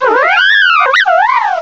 cry_not_lopunny.aif